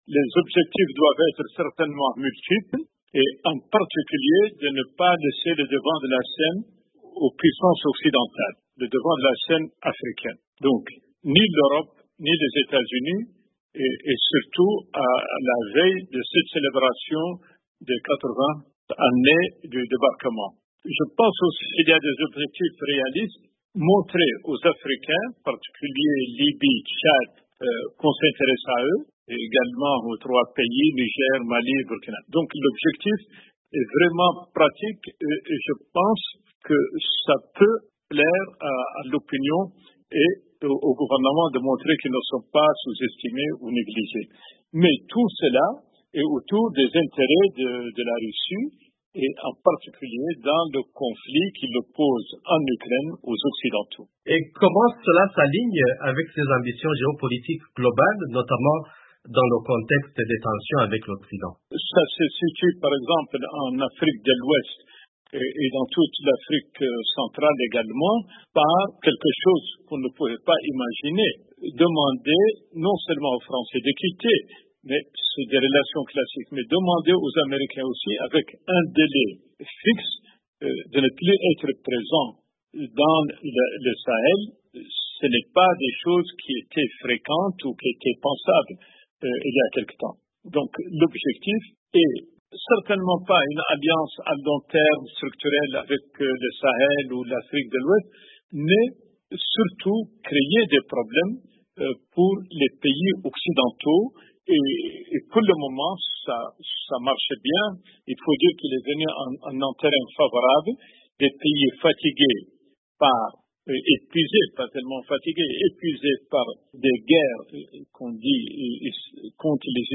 a joint à Nouakchott